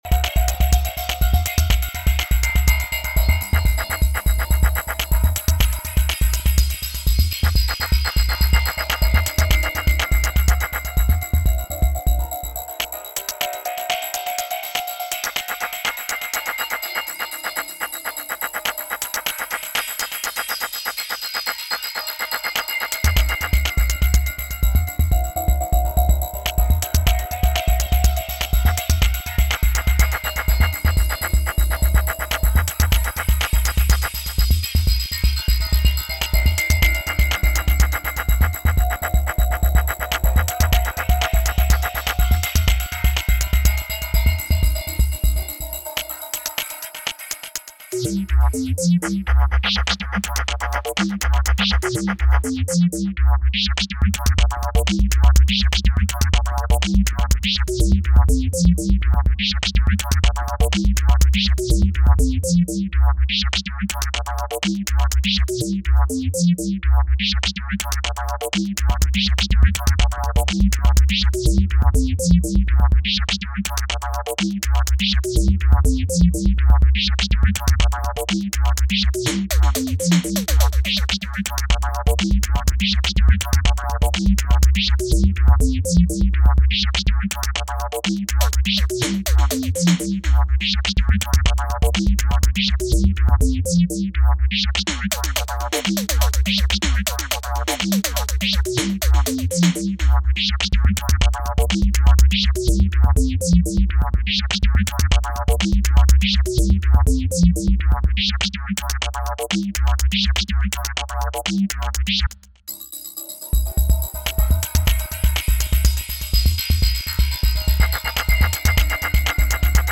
Home > Music > Electronic > Running > Chasing > Restless